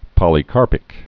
(pŏlē-kärpĭk) also pol·y·car·pous (-pəs)